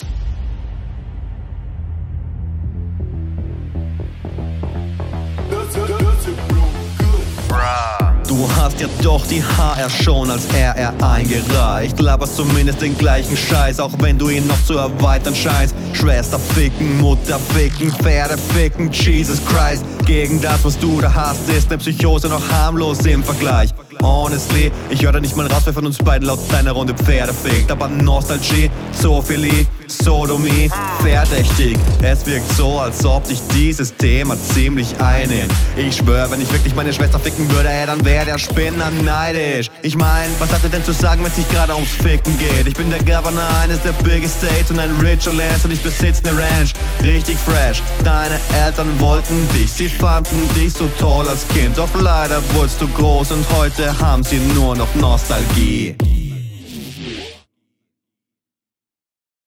Dein Sound leidet auch etwas unter dem Beat tbh. Aber chilliger Flow mit gutem Text.